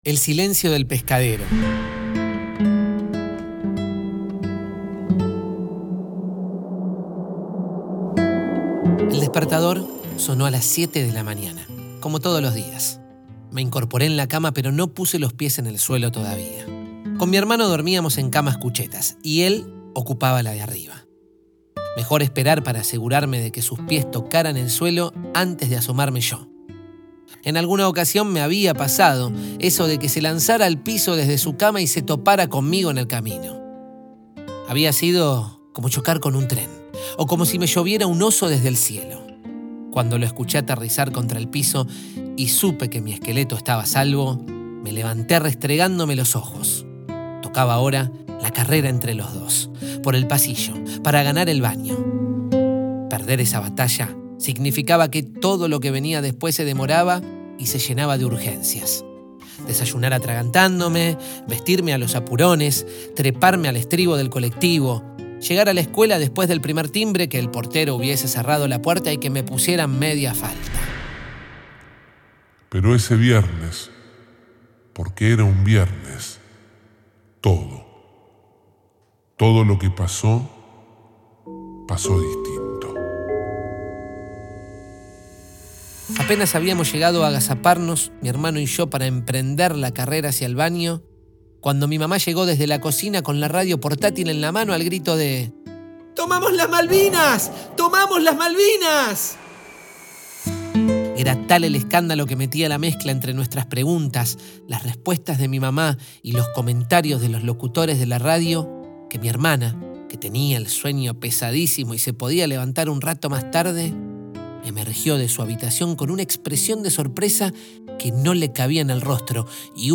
Compartir este cuento contado: